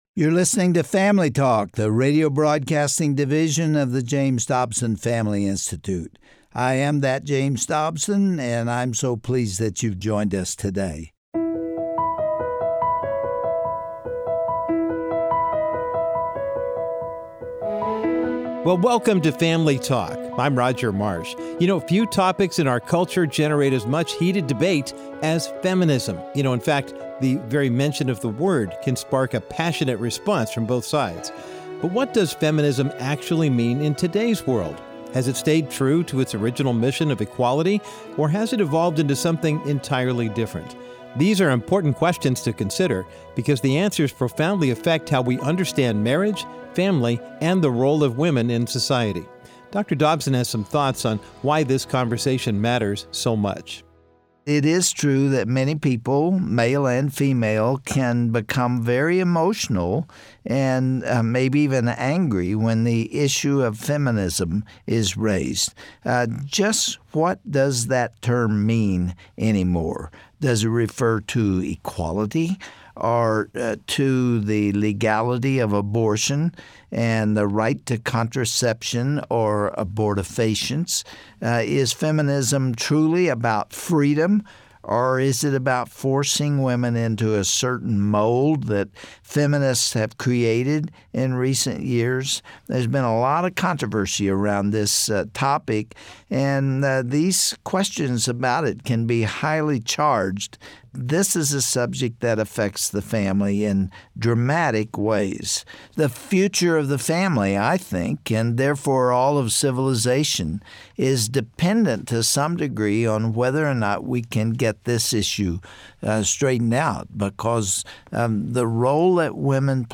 Host Dr. James Dobson Guest(s